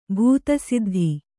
♪ bhūta siddhi